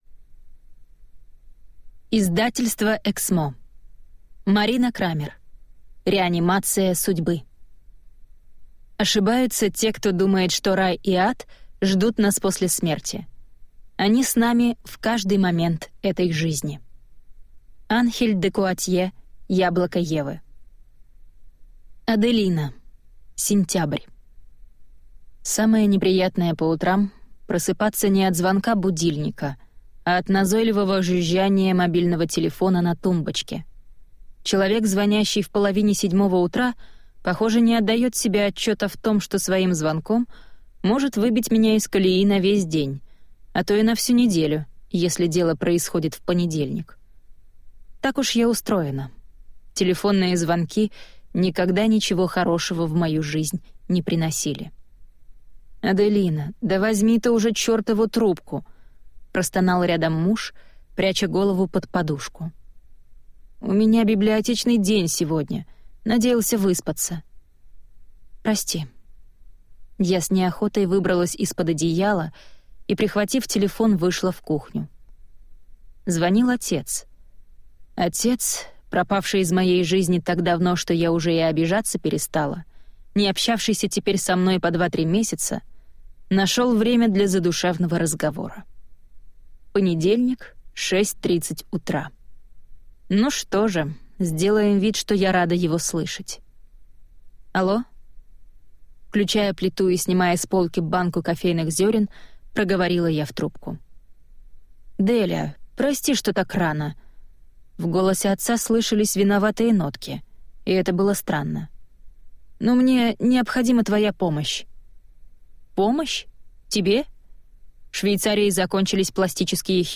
Аудиокнига Реанимация судьбы | Библиотека аудиокниг